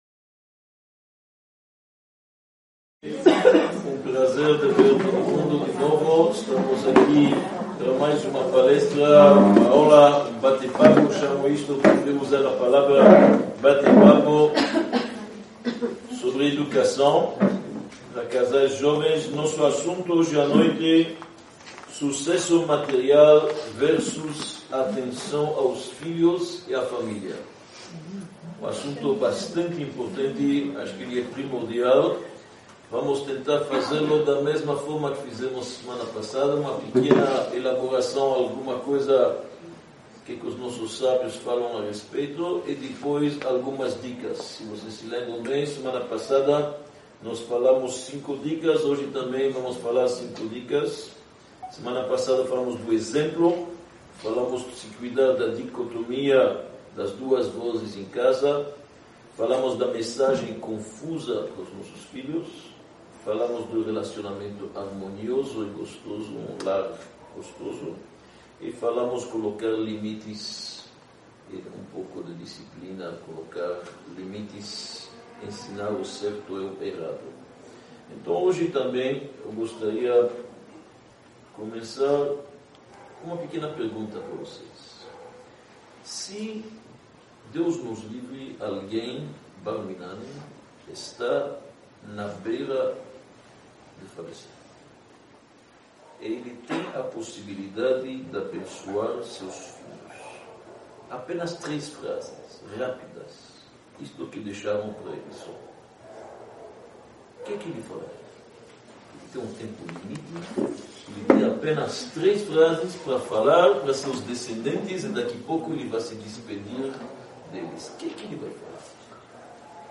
Palestra-Parte-2_-Sucesso-material-x-atenção-aos-filhos-e-à-família-1.mp3